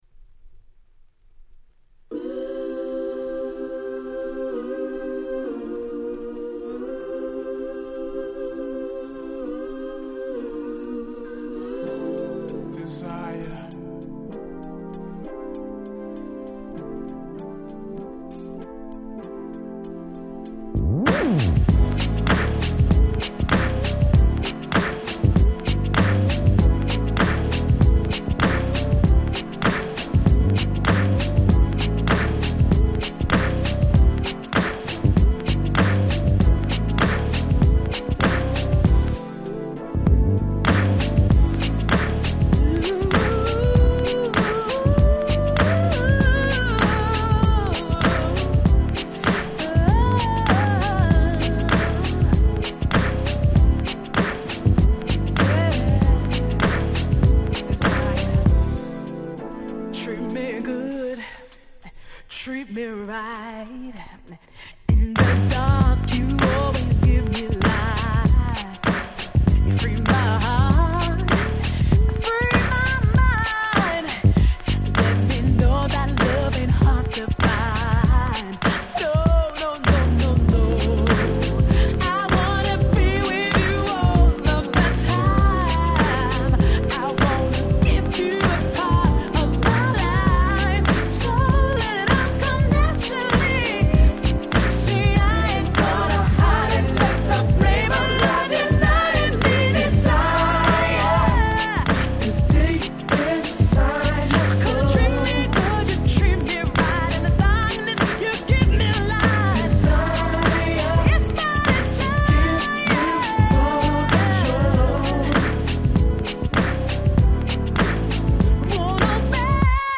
UK R&B Classic !!